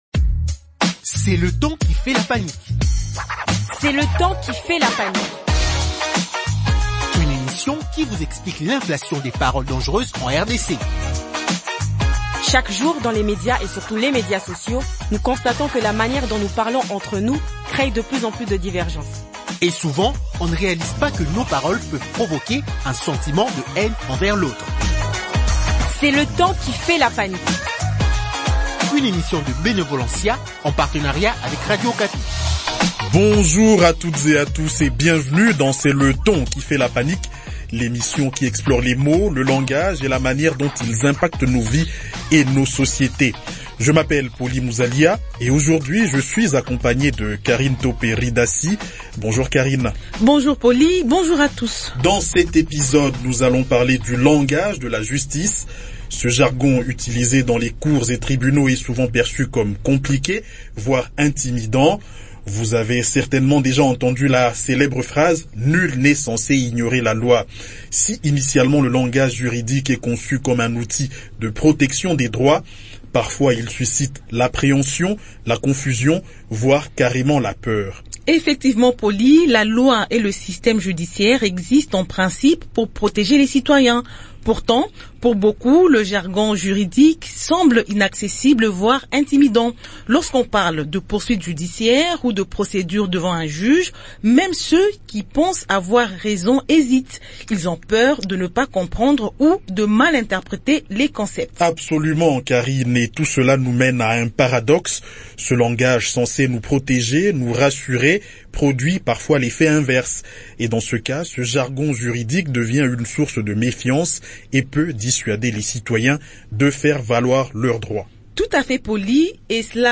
Pour en parler, deux invités :